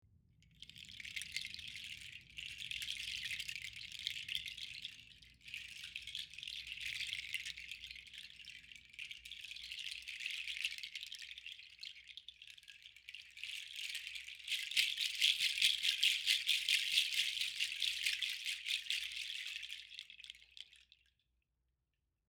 Afroton ATB778 Tobillera on Ribbon, ca 130 nut shells, L 55 cm
• tobillera nut shells
• gentle,soothing, enchanting sound
• can be shaken or tapped rhythmically